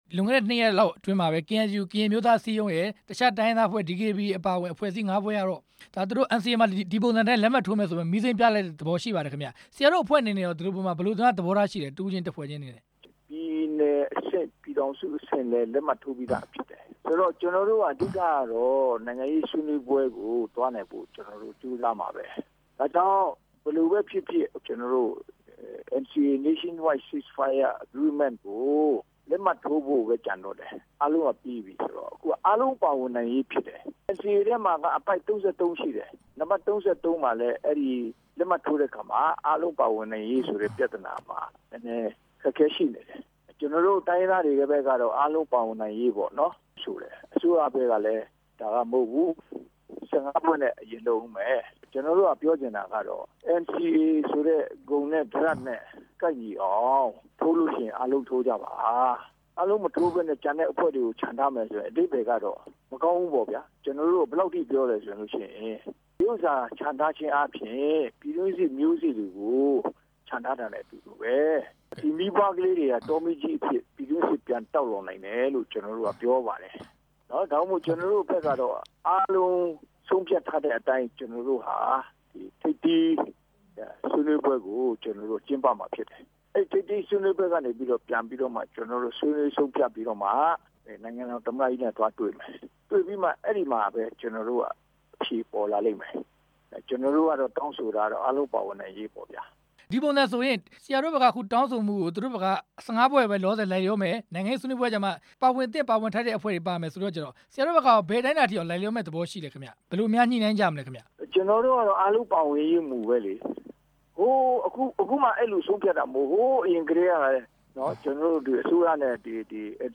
အပစ်အခတ်ရပ်စဲရေး စာချုပ်အကြောင်း မေးမြန်းချက်